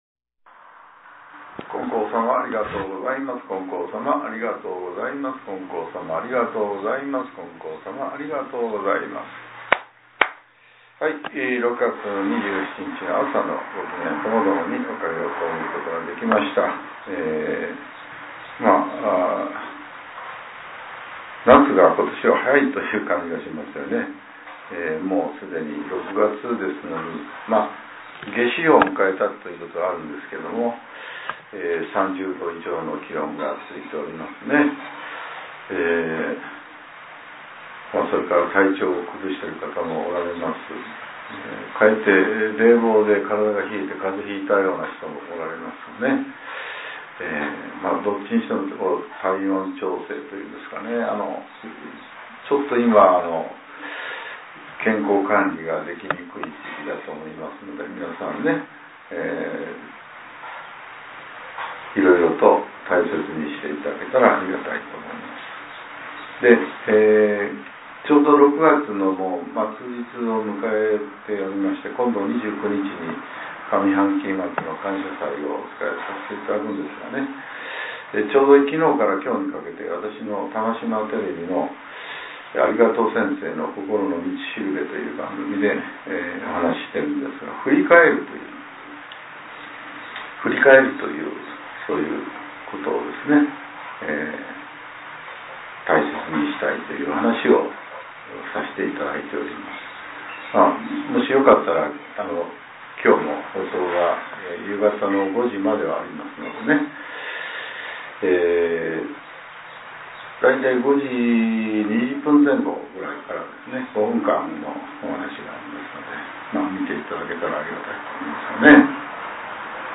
令和７年６月２６日（朝）のお話が、音声ブログとして更新させれています。